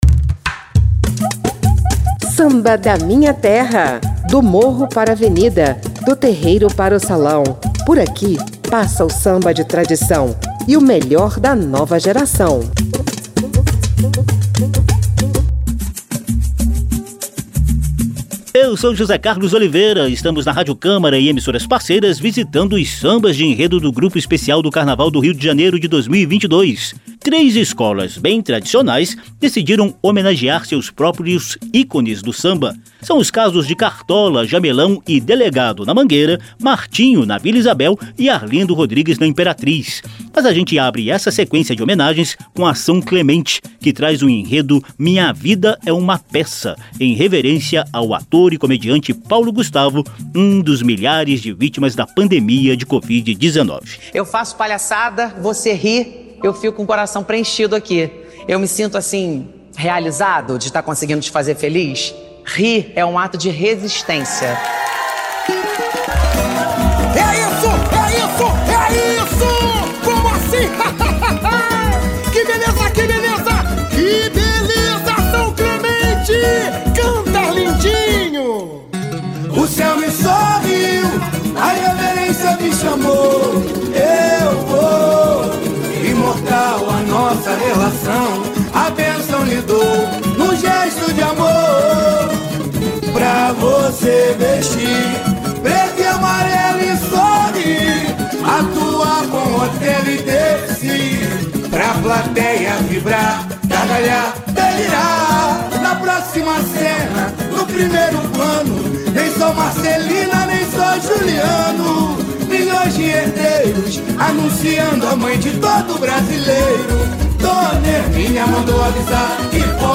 Samba da Minha Terra mostra a atual produção cari oca de sambas de enredo.